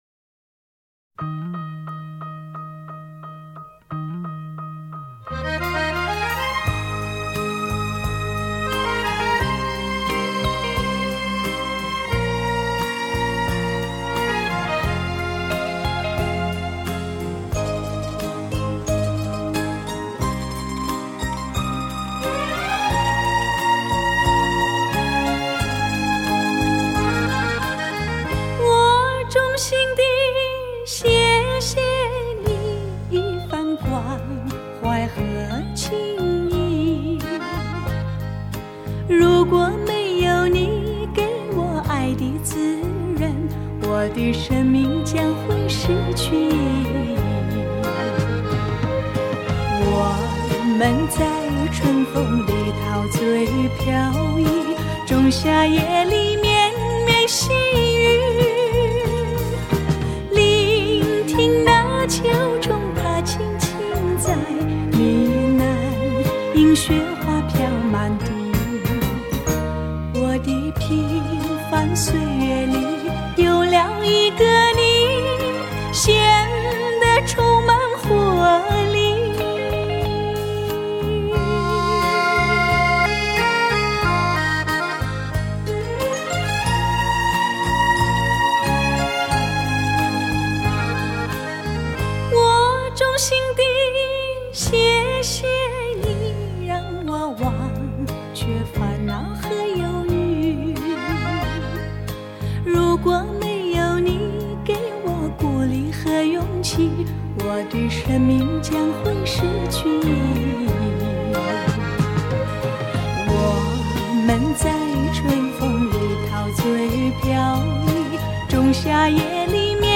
划时代顶级发烧母版处理
音色更接近模拟(Analogue)声效
强劲动态音效中横溢出细致韵味